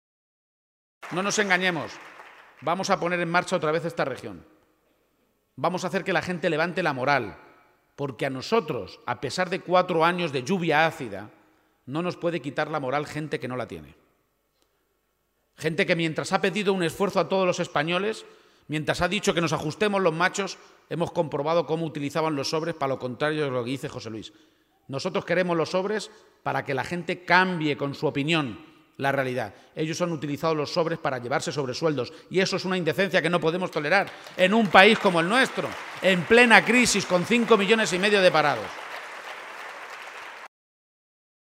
El candidato socialista realizó esta manifestaciones en un acto público ante más de 500 personas celebrado en la Casa de la Cultura de Campillo de Altobuey, localidad de La Manchuela Conquense donde finalizó la visita de Page a esta comarca, tras estar en Motilla del Palancar, Villanueva de la Jara, Minglanilla y La Pesquera, localidades donde se reunió con colectivos y organizaciones sociales.